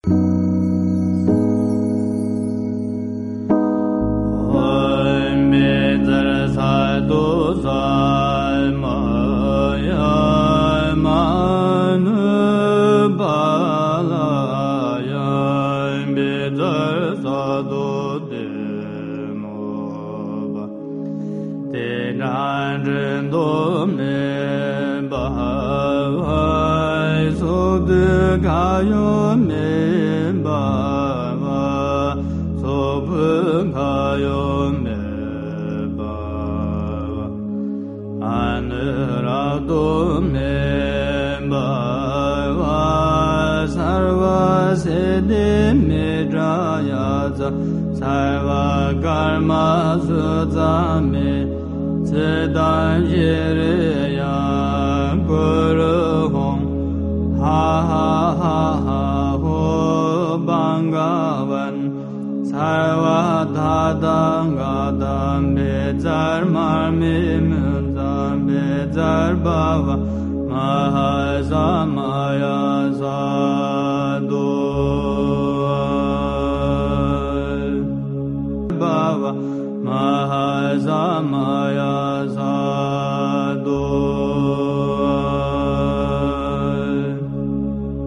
Wunderschön gesungen mit sehr harmonischer Hintergrundmusik